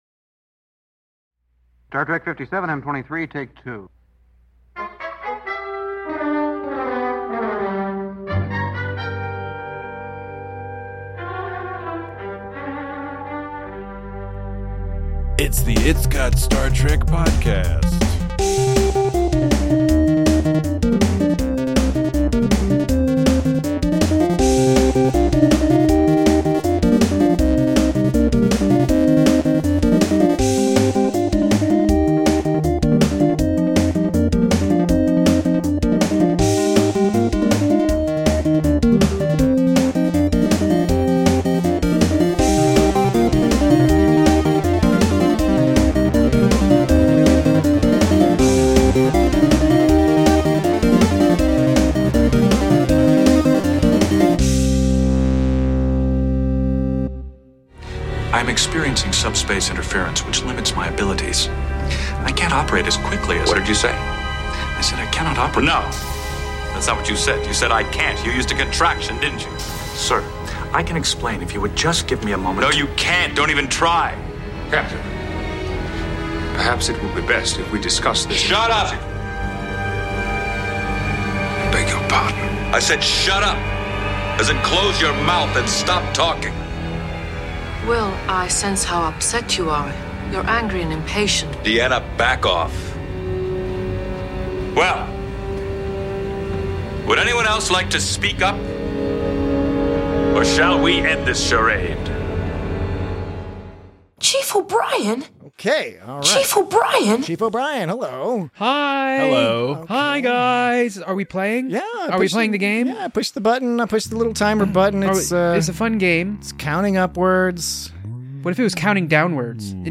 Join your comfortable hosts as they discuss the benefits of cozy, middle-of-the-road Trek.